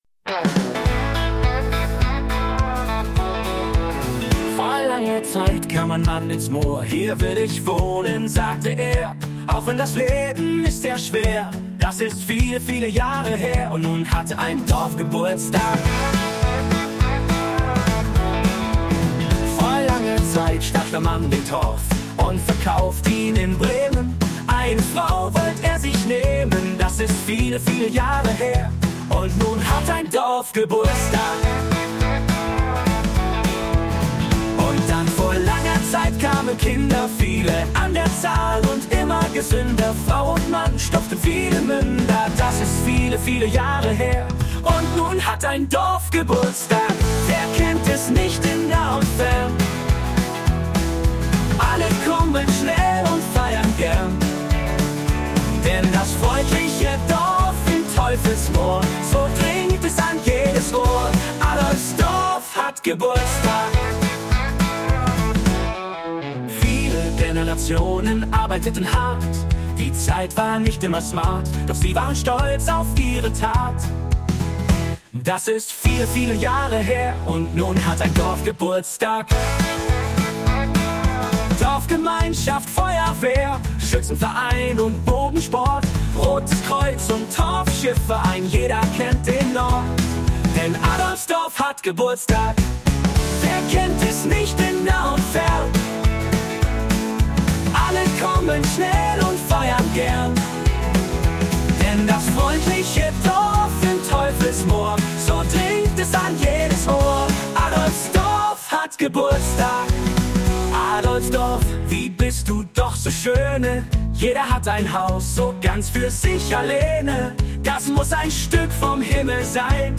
Das "River Quartett":
Ich habe mir erlaubt ein wenig mit Künstlicher Intelligenz zu spielen und diese drei "Tracks" (als MP3) sind dabei herausgekommen.